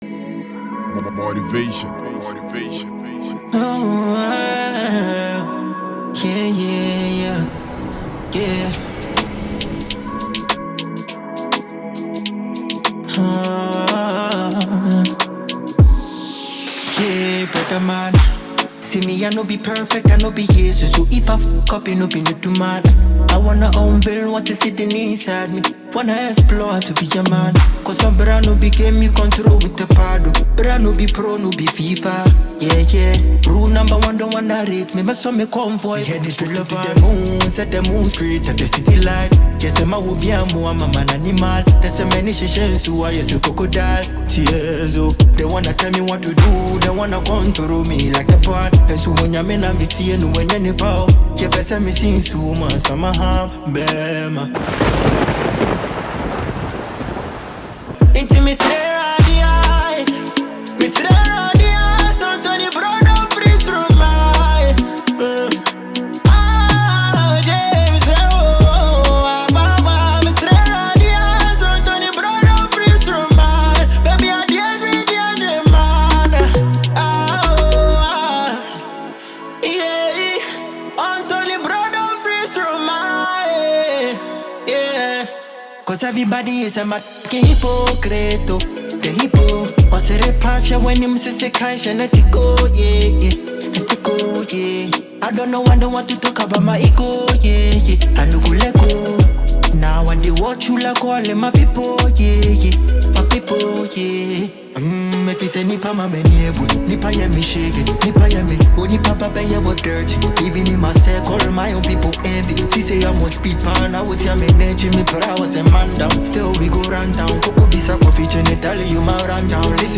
smooth vocals, the catchy hooks
blends Afrobeat with contemporary sounds
With its high energy tempo and catchy sounds